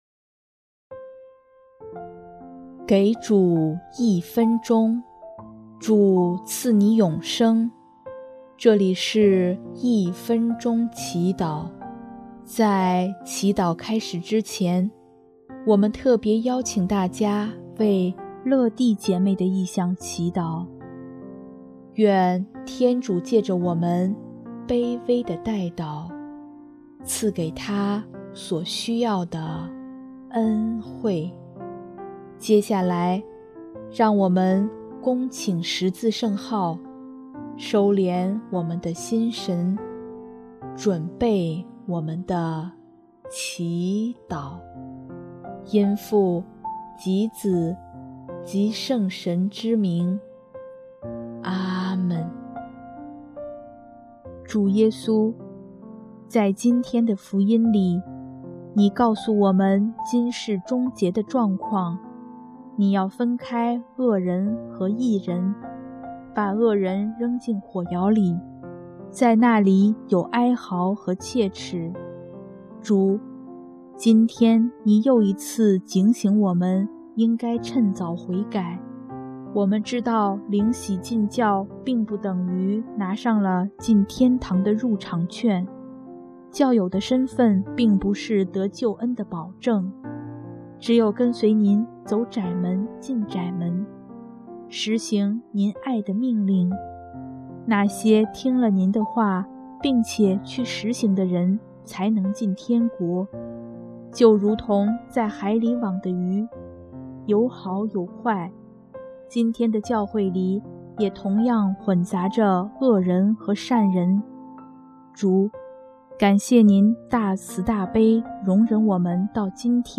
音乐：第二届华语圣歌大赛获奖歌曲《山里的牧羊人》